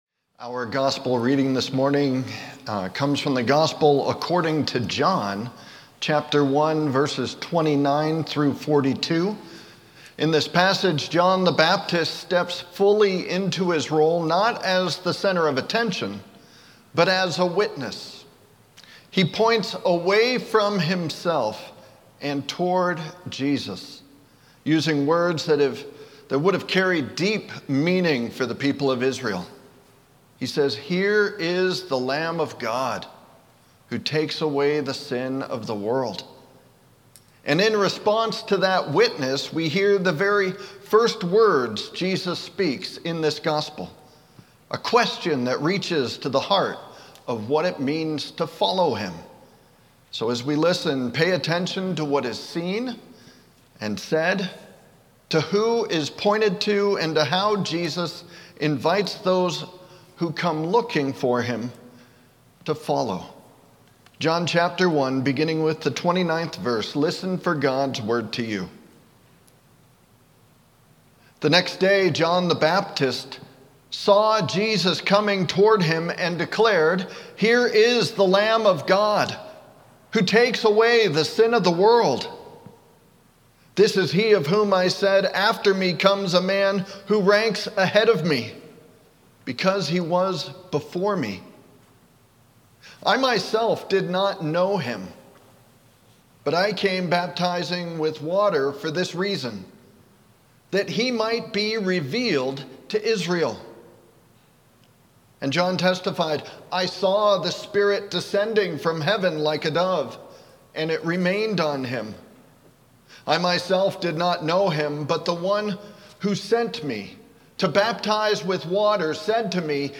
Audio Sermons details
Sermon+1-18-25.mp3